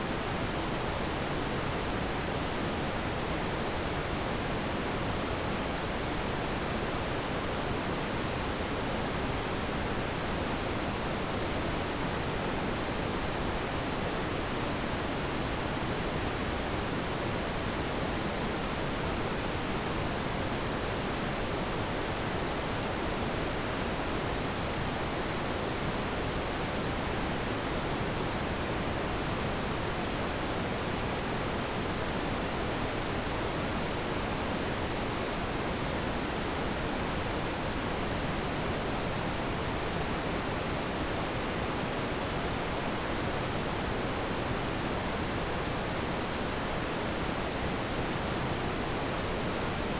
noise1.wav